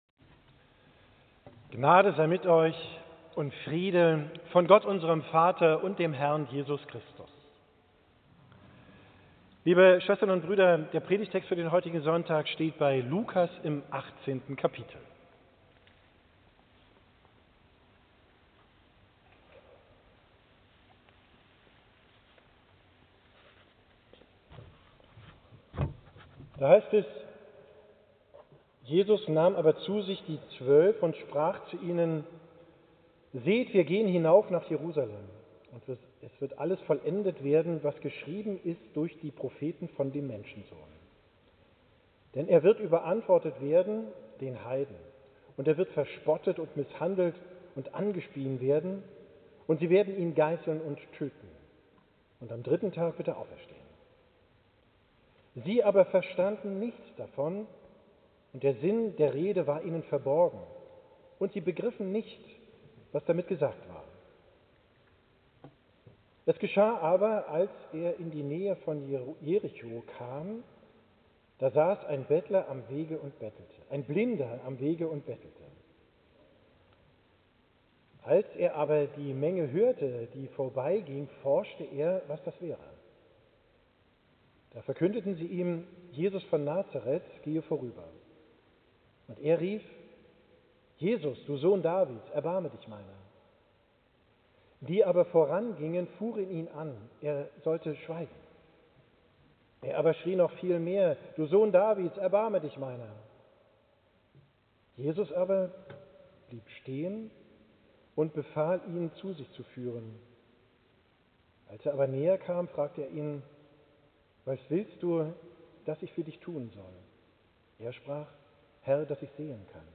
Predigt vom Sonntag Estomihi, 15.